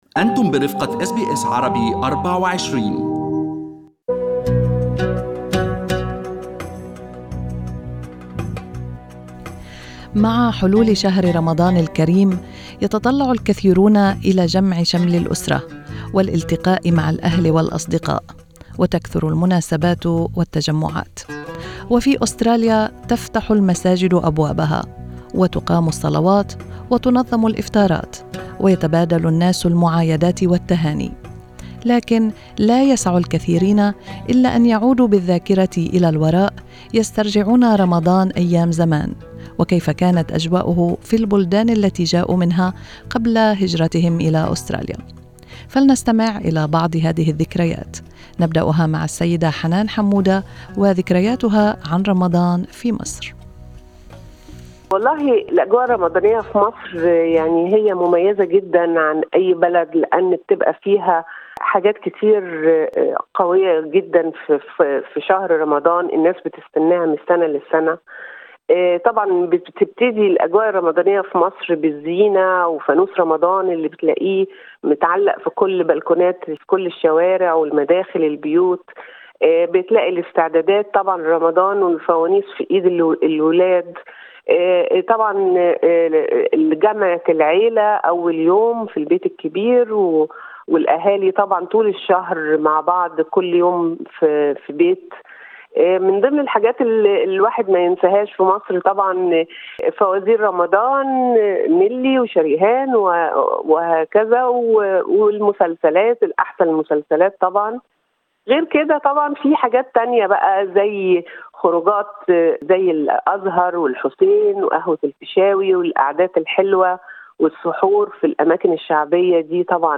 وفي هذا البرنامج الخاص